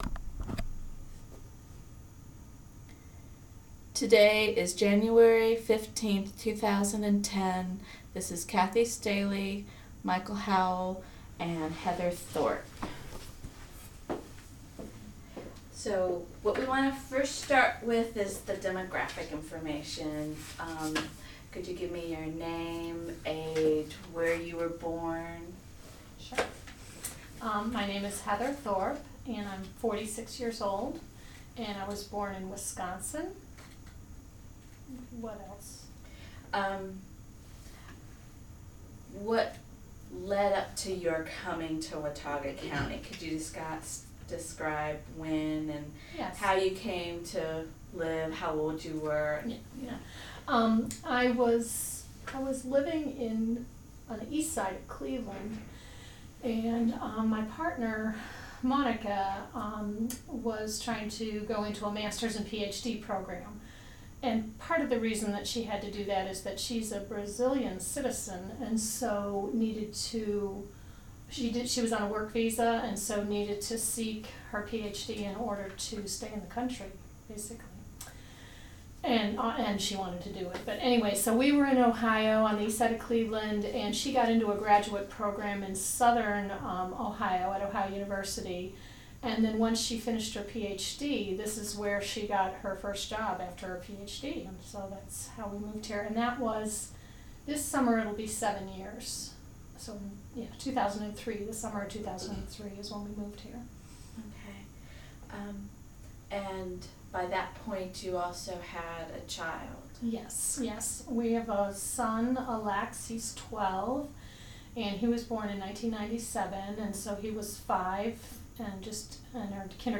Interview recording